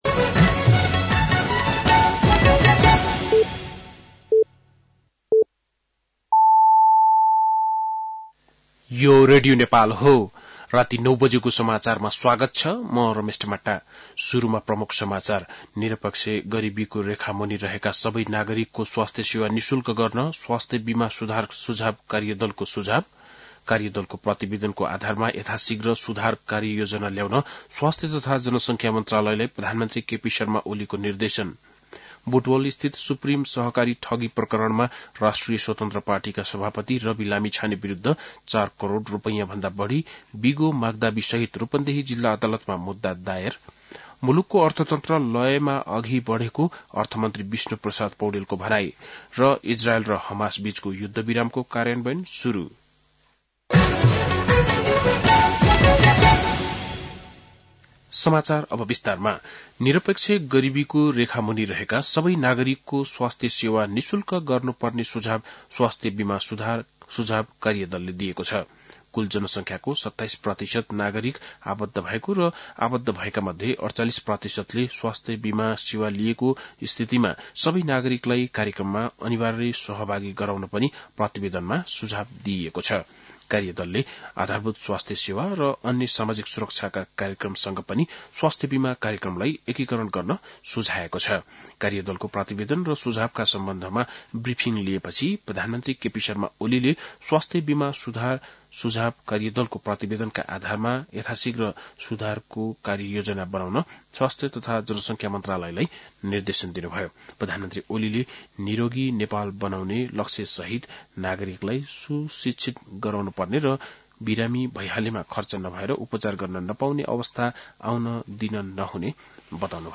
बेलुकी ९ बजेको नेपाली समाचार : ७ माघ , २०८१
9-PM-Nepali-News-10-6.mp3